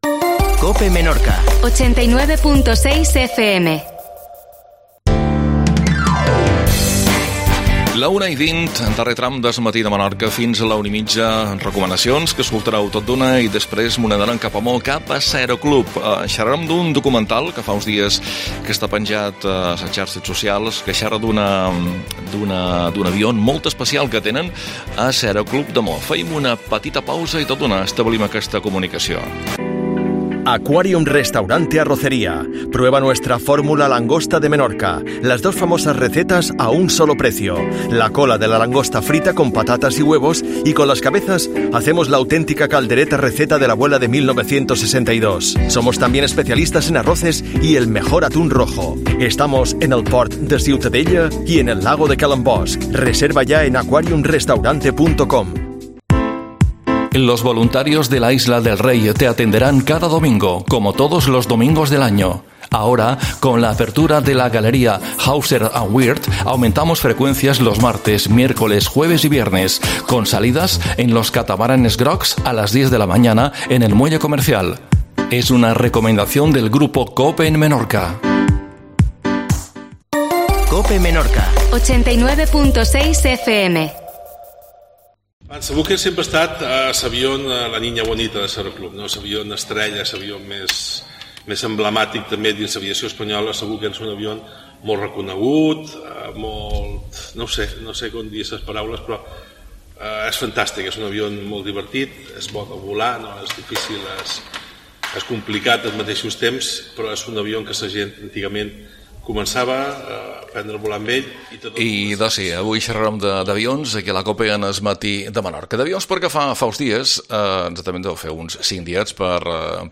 Documental sobre la Bücker (EC-DAI) que es troba a l'Aeroclub de Mahon